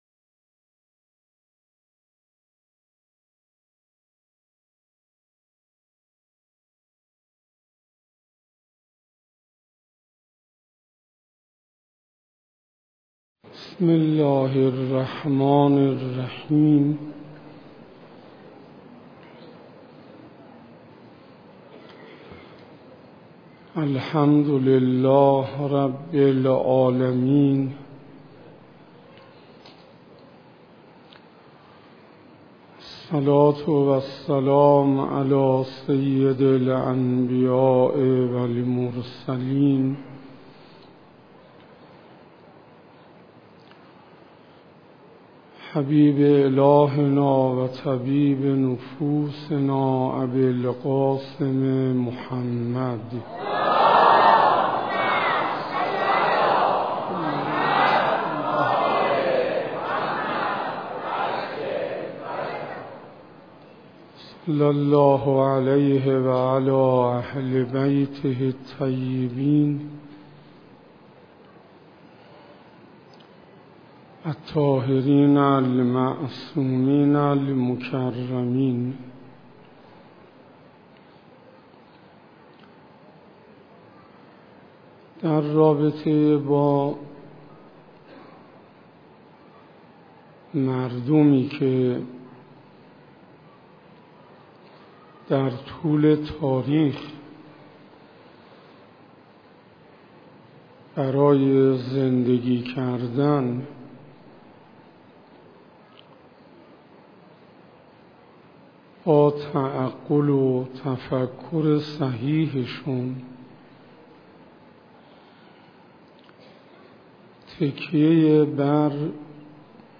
سخنرانی حجت الاسلام انصاریان